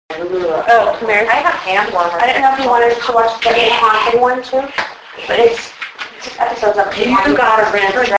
Audio Evidence (EVP/EAP)
You can tell that it isn't one of us do to the signature EVP/EAP type voice. Again, the electronic sound is the fan from the computer system used for the DVR system. This audio was left at base.